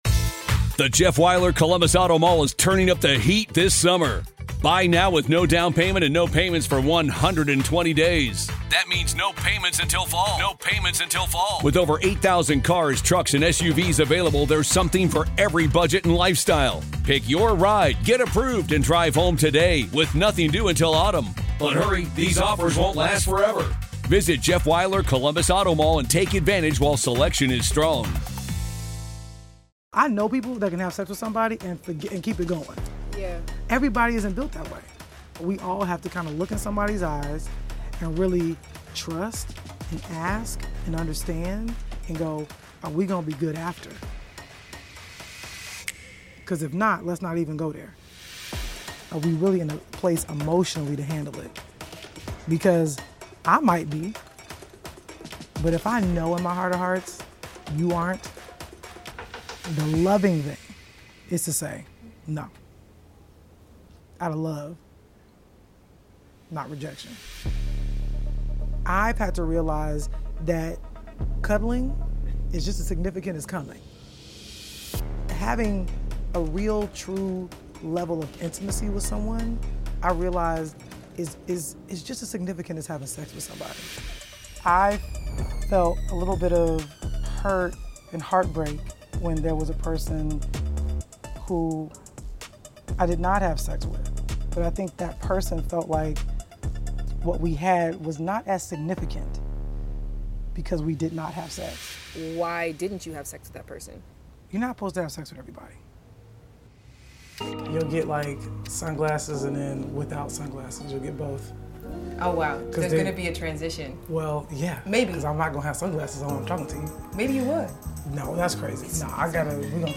In this episode of Lovers and Friends , Shan Boodram sits down with Emmy-winning writer, producer, and actress Lena Waithe ( Queen & Slim , Master of None , The Chi ) for an eye-opening conversation about intimacy, love, and meaningful connections.